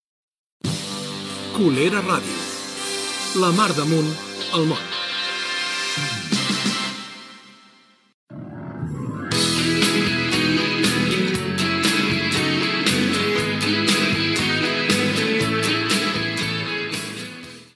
Indicatiu de l'emissora i tema musical